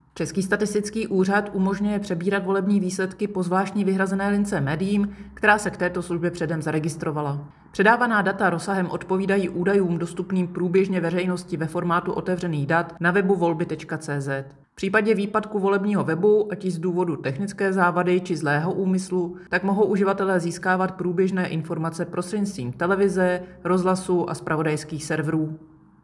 Vyjádření Evy Krumpové, 1. místopředsedkyně ČSÚ ke zveřejňování výsledků voleb, soubor ve formátu MP3, 465.94 kB